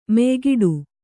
♪ meygiḍu